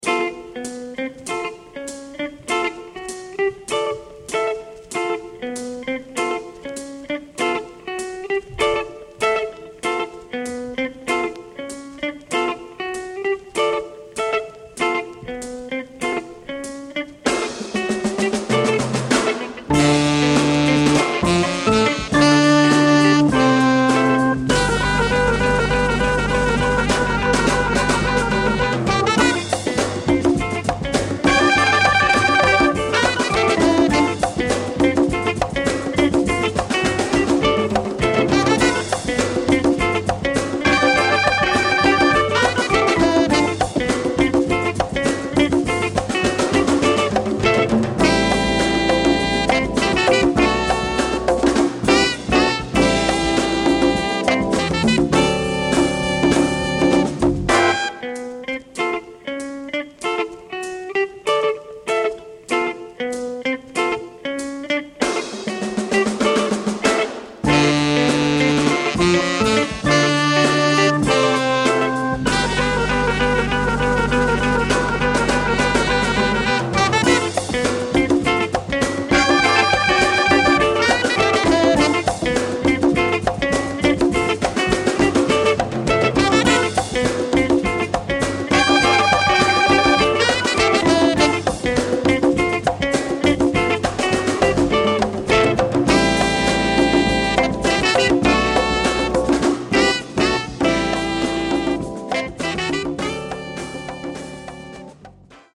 Jazz-funk essentials.